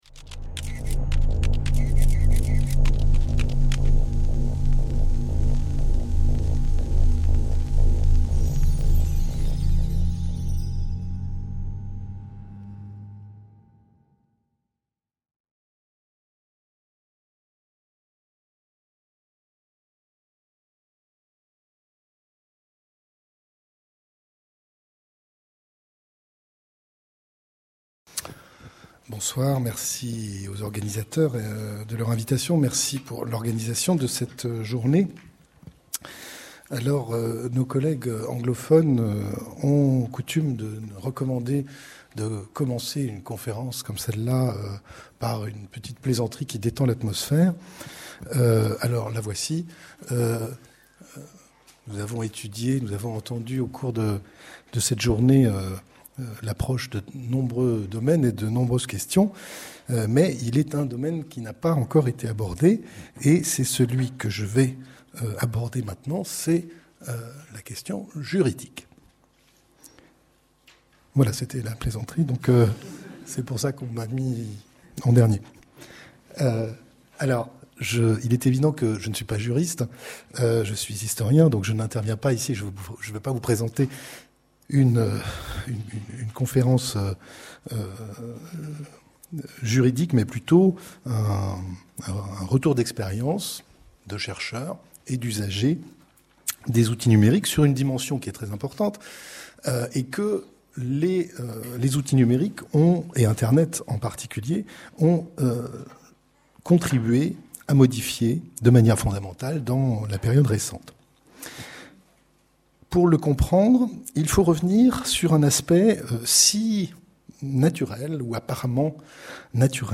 Journée d’études de la Commission des usagers des technologies de l’information et de la communication électronique de l’EHESS (CUTICE)